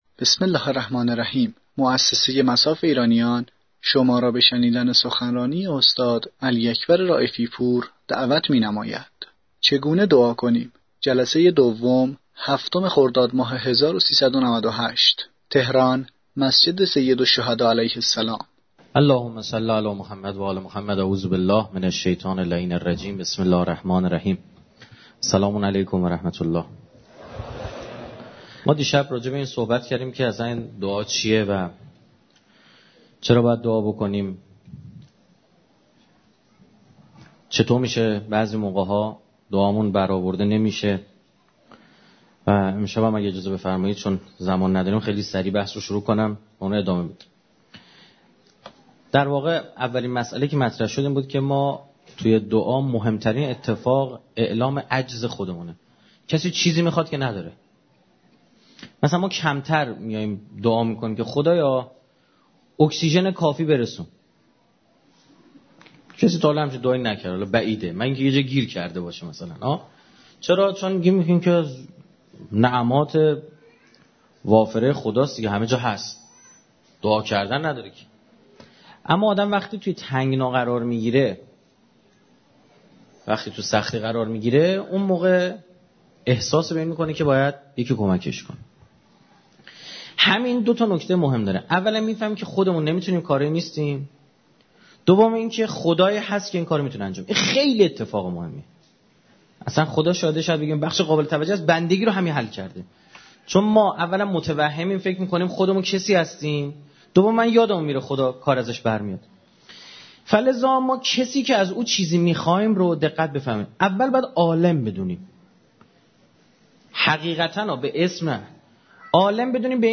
دانلود سخنرانی استاد رائفی پور (چگونه دعا کنیم؟(